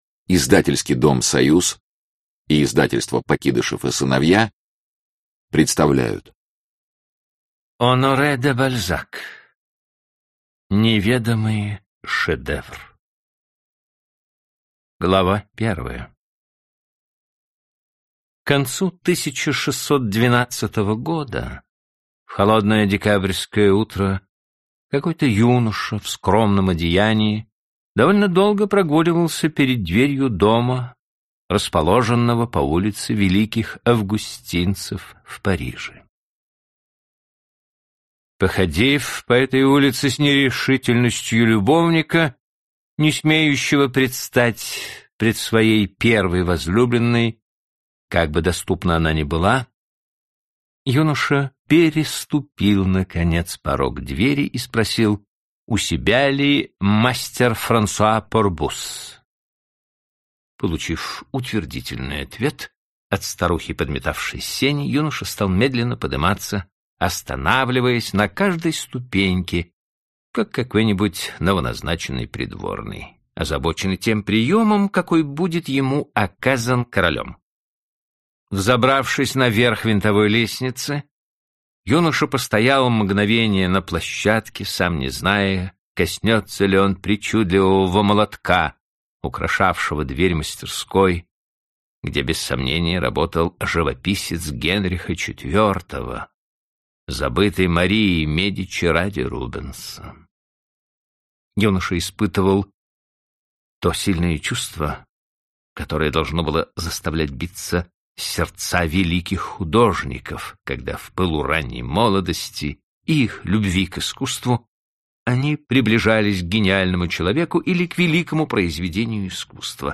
Аудиокнига Неведомый шедевр | Библиотека аудиокниг